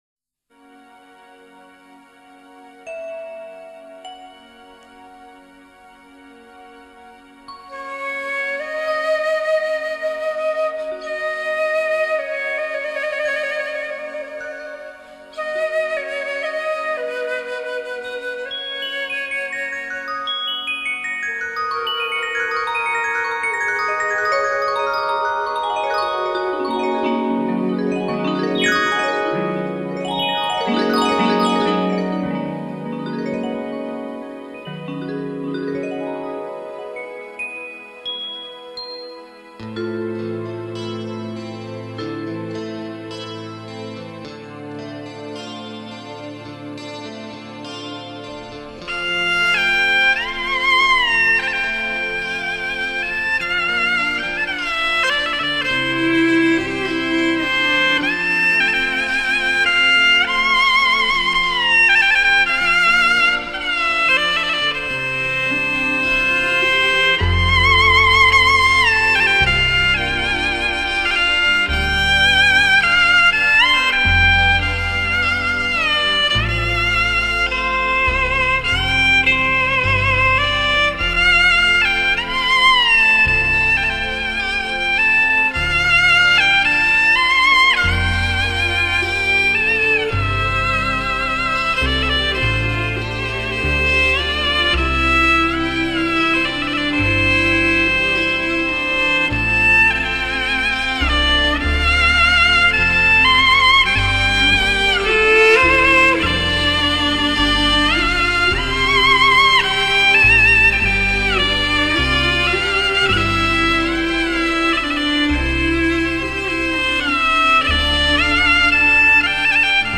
萧吹微风，筝波荡水，一轮圆月映照杨柳岸边，胡琴唱和着美妙的南粤 夜曲。